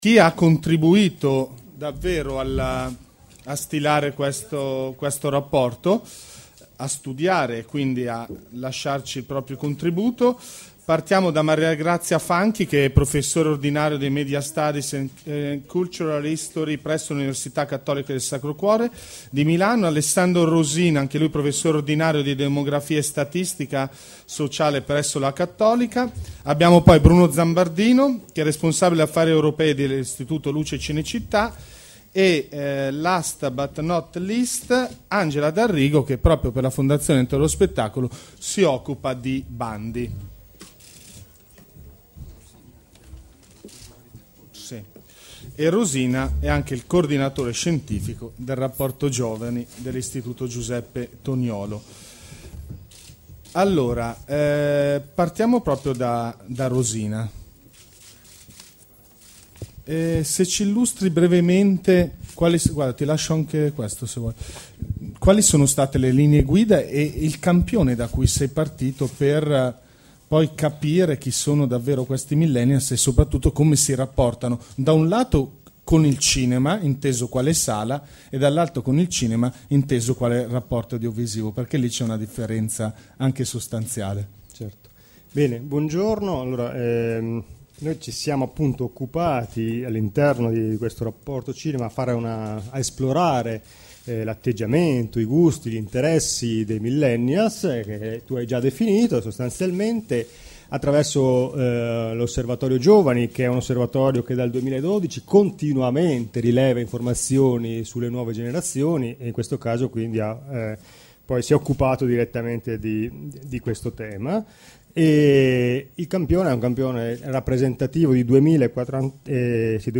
Presso la Sala Marconi di Radio Vaticana (Piazza Pia, 3 – Città del Vaticano) è stato presentato il Rapporto Cinema 2018 realizzato dalla Fondazione Ente dello Spettacolo.